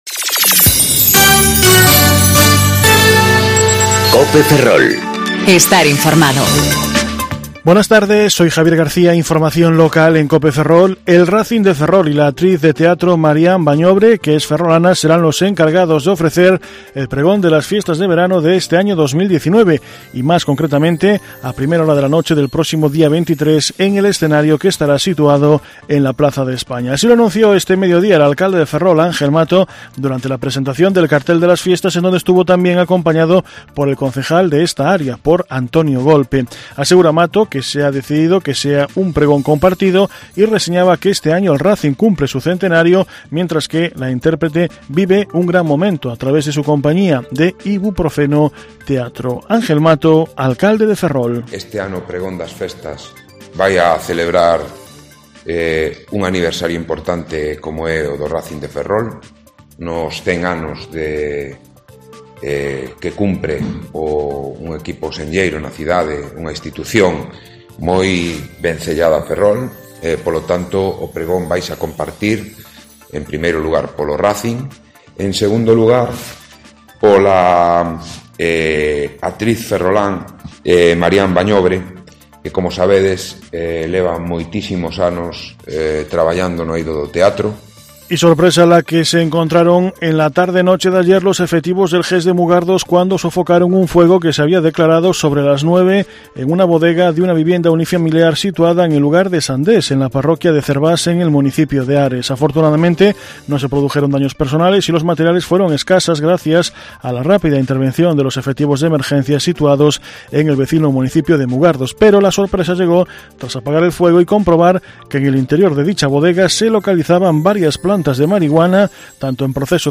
Informativo Mediodía Cope Ferrol 16/08/2019 (De 14.20 a 14.30 horas)